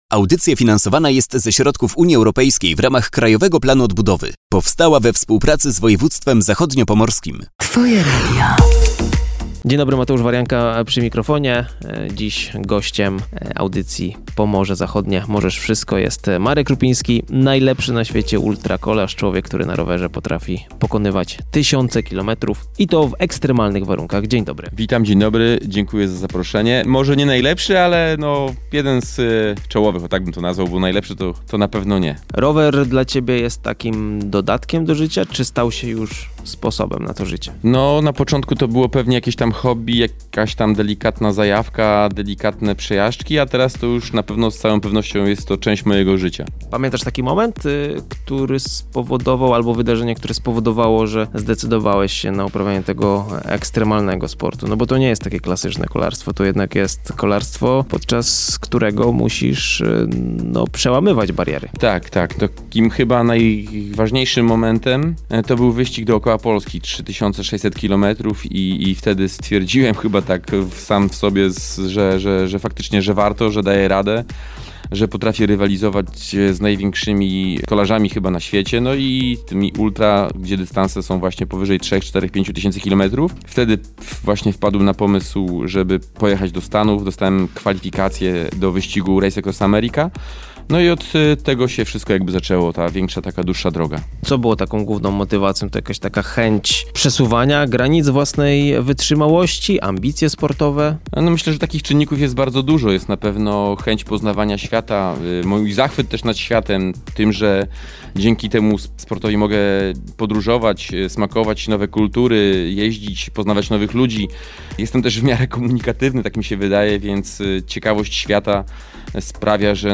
Wywiad, który mogliście usłyszeć na antenie Twojego Radia, jest już dostępny w formie podcastu!